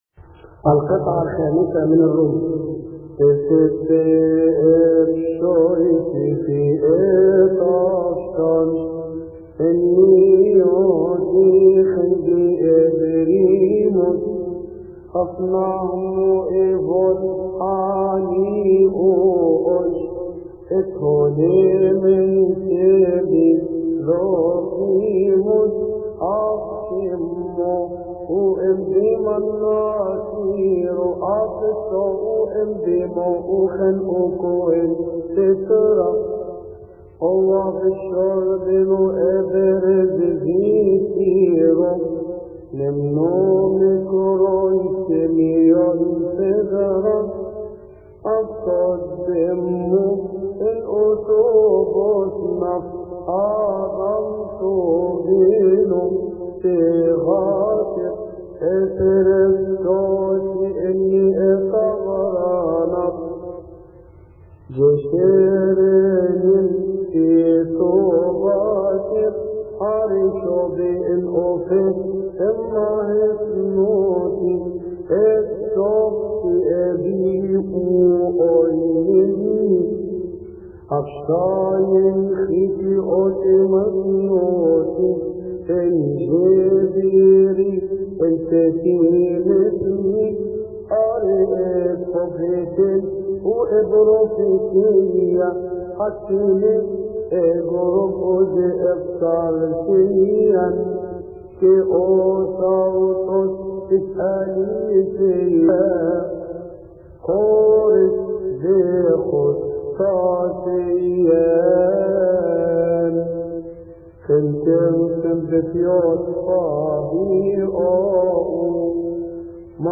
المرتل